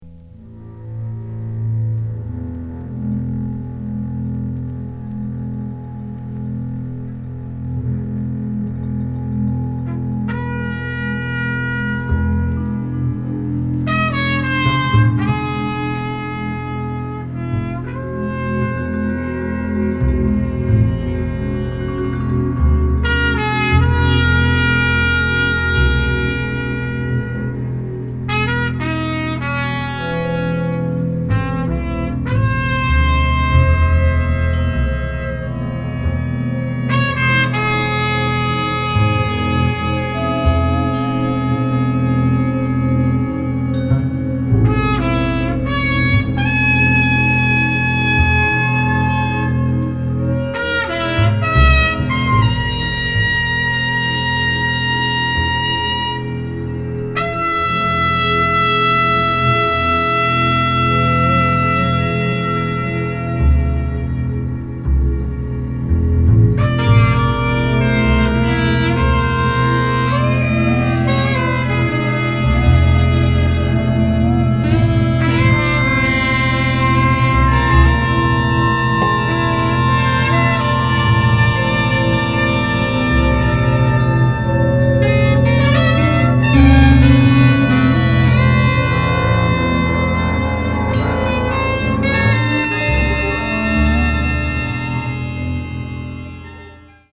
分厚い音の壁と沈黙とが迷宮状に配置された極めて構築的な仕上がり。